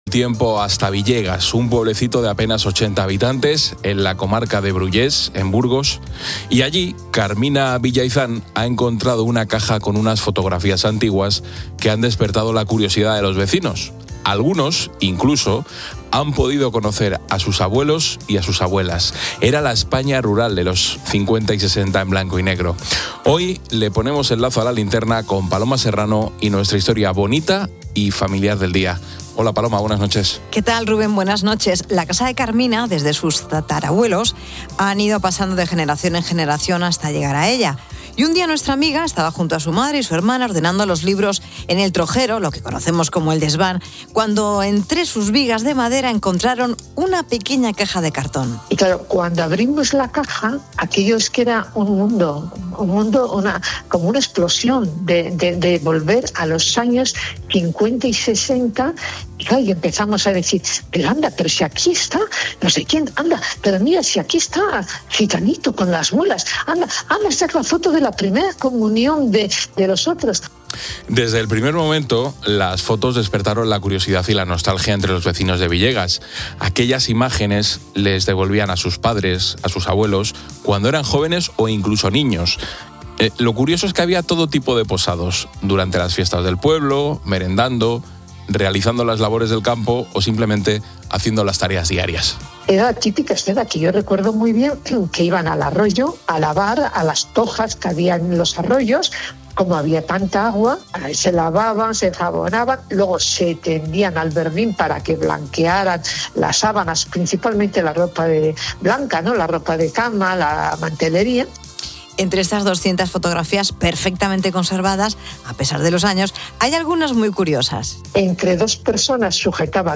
cuentan la historia bonita del día que ocurrió en Villegas, un pueblo de Burgos